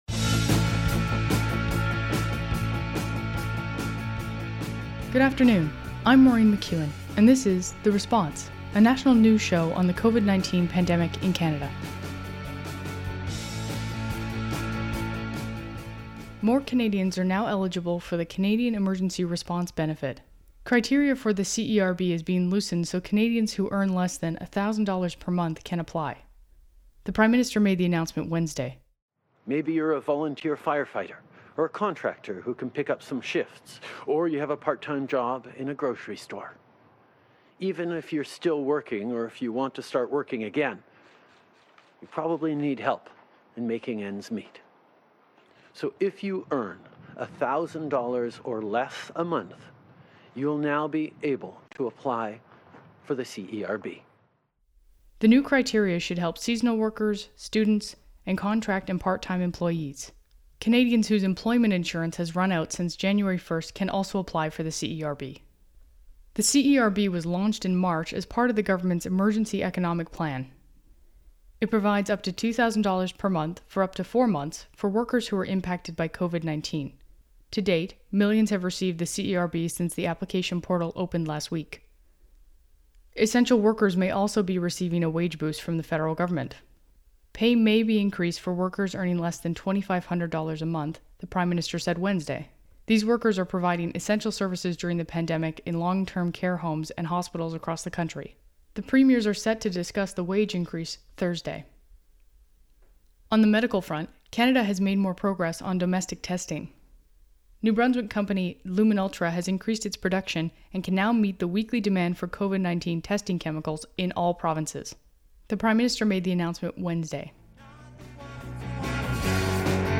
National News Show on COVID-19
Theme: "Headed South" by The Hours.
Type: News Reports